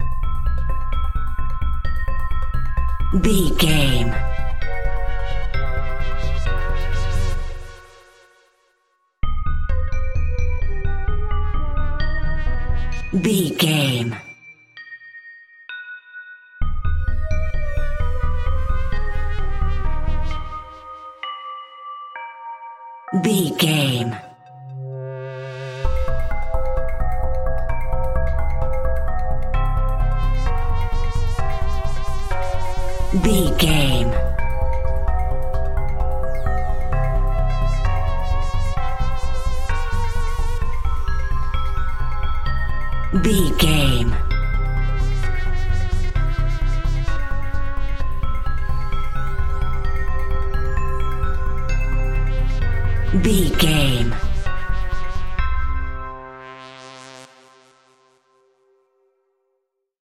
Augmented
synthesizer
eerie
hypnotic
medium tempo
ominous
drum machine